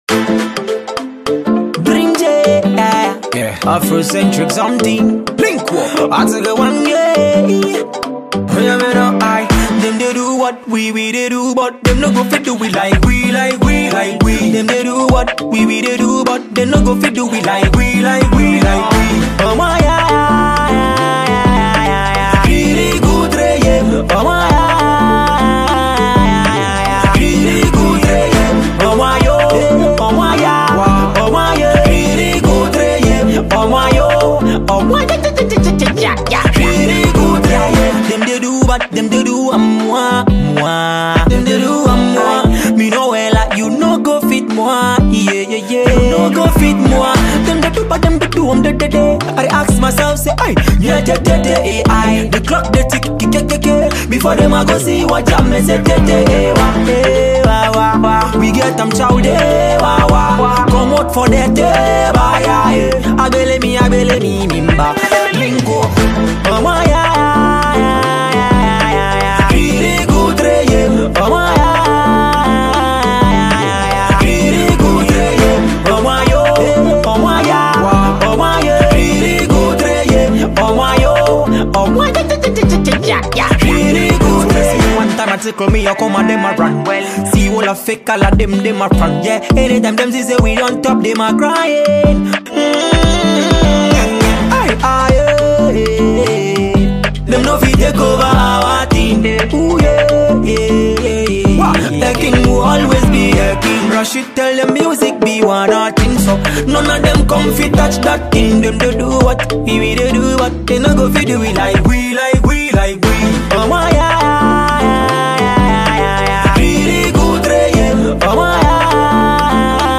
Ghana MusicMusic
catchy midtempo song